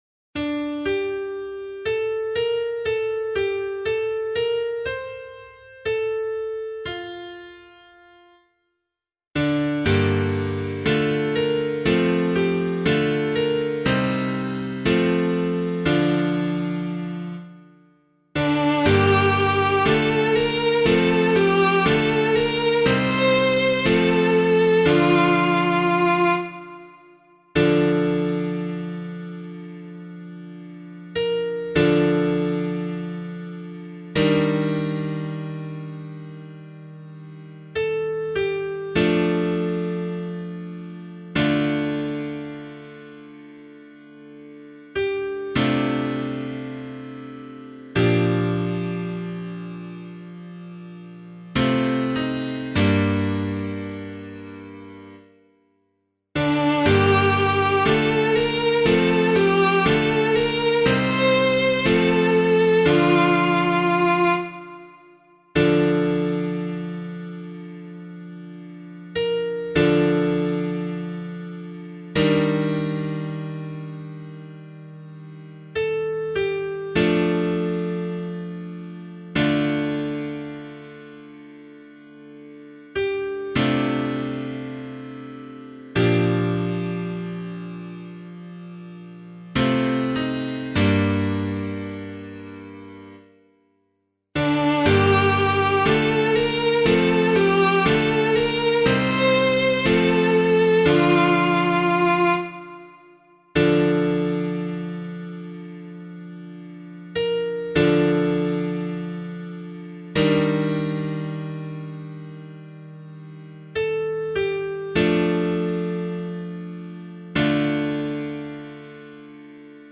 014 Lent 2 Psalm C [APC - LiturgyShare + Meinrad 3] - piano.mp3